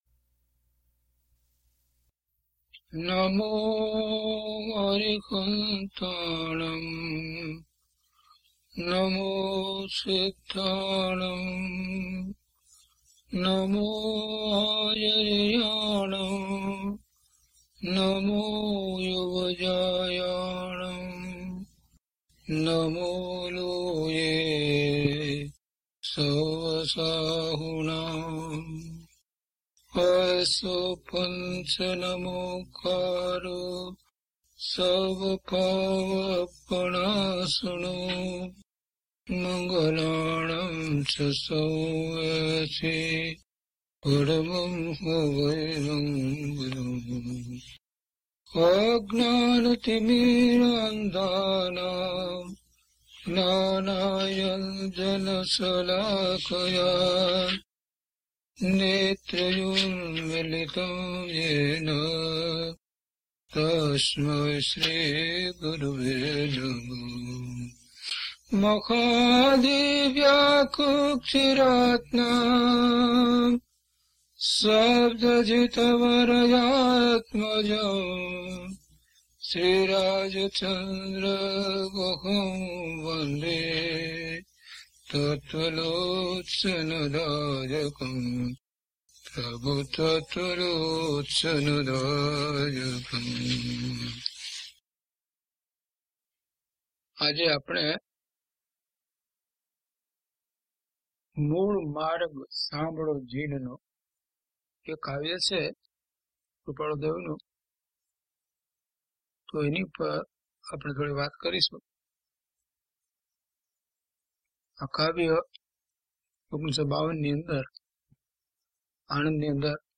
DHP001 Mul Marag Sanbhalo Jinano - Pravachan.mp3